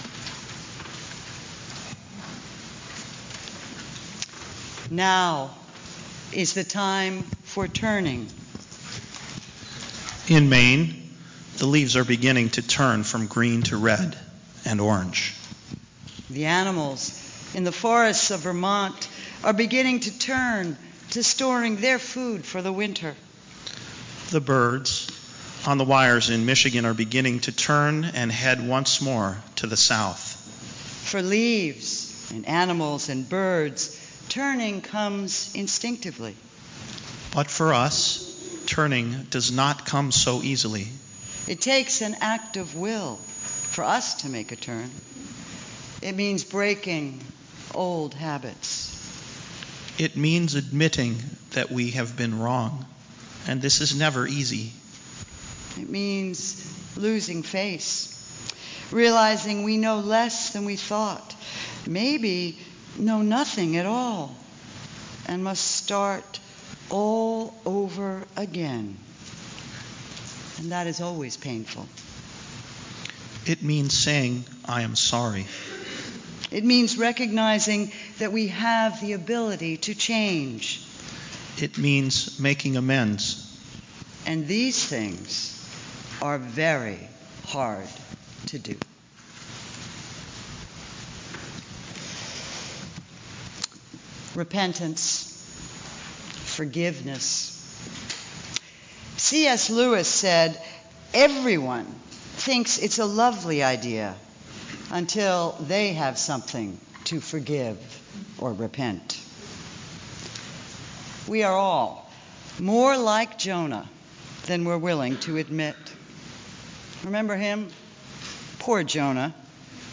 by | Sep 8, 2013 | Recorded Sermons | 0 comments